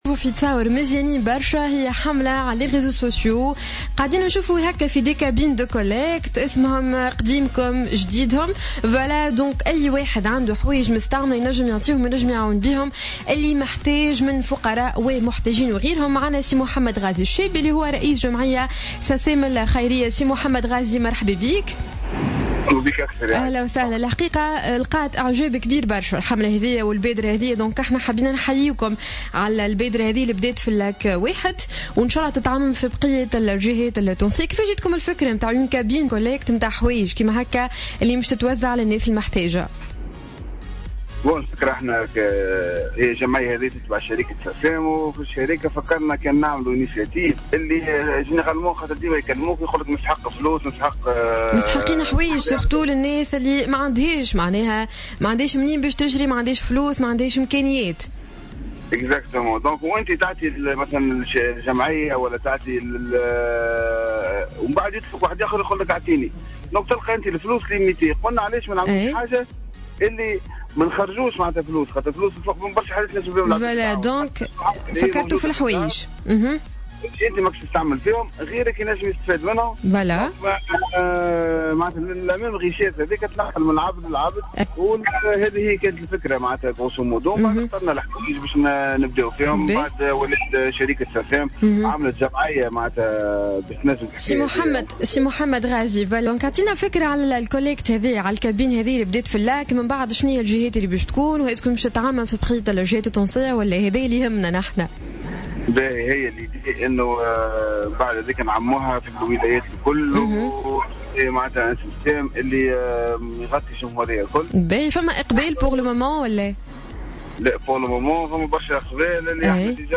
lors de son passage sur les ondes de Jawhara FM